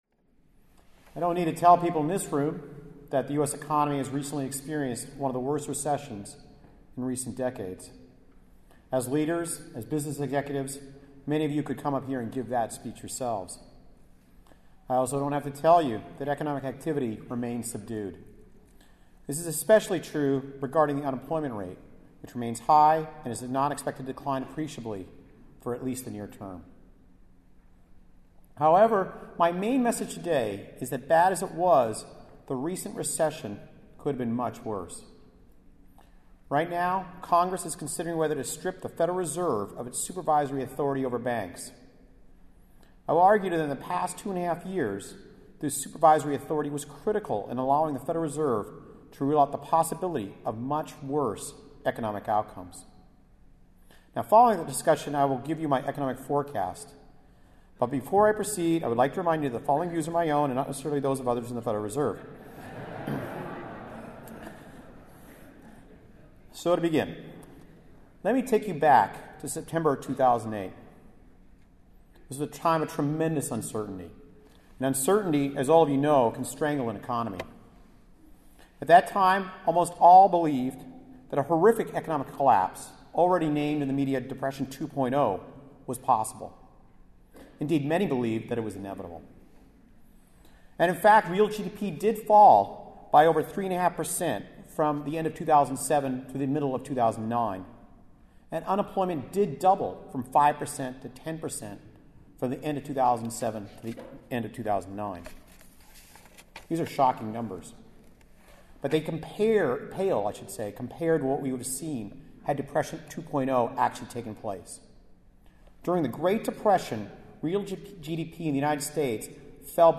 Speech (audio) I don’t need to tell people in this room that the U.S. economy has recently experienced one of the worst recessions in recent decades.